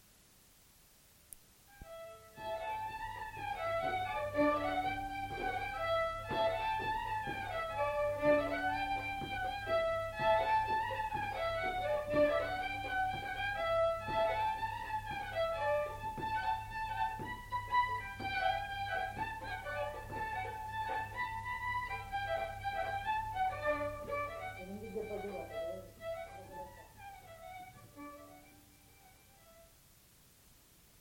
Rondeau
Aire culturelle : Lomagne
Genre : morceau instrumental
Instrument de musique : violon
Danse : rondeau
Notes consultables : Deux violons.